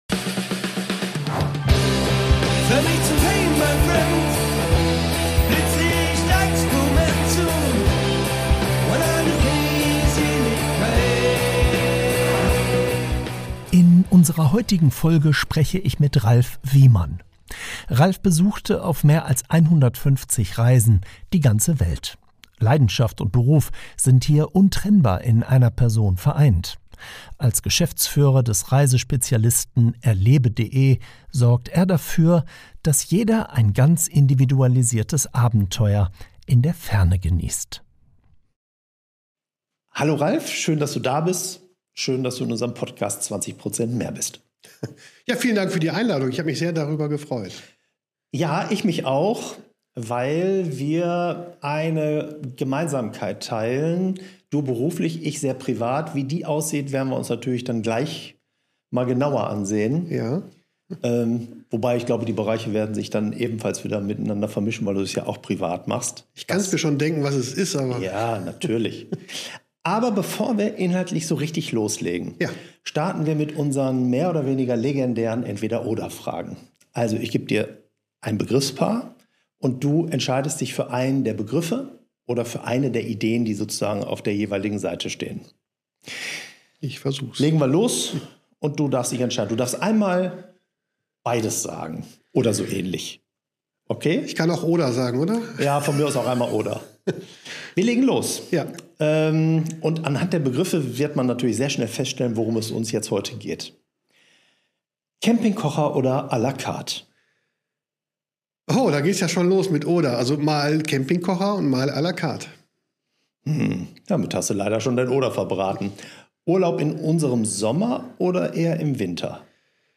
Ein inspirierendes Gespräch über den Mut zum Aufbruch, die Verantwortung des Reisens und die Erkenntnis, dass Vertrauen die wichtigste Währung ist.